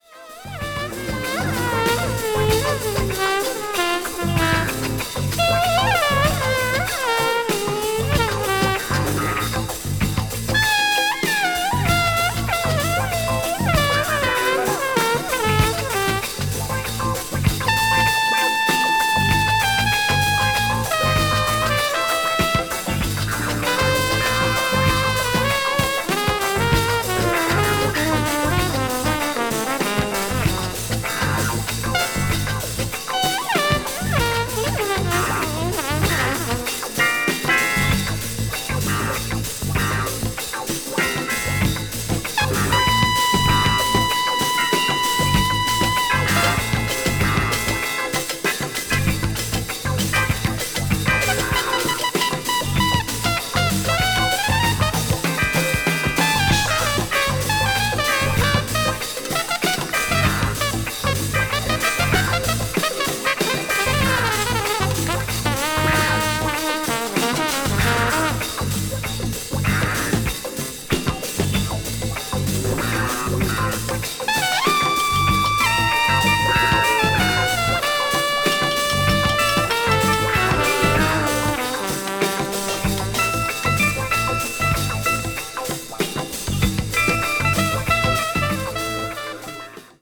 B2はもろにMiles節が炸裂していますが、音の鳴りはまったく違います。
crossover   electric jazz   fusion   jazz funk   jazz groove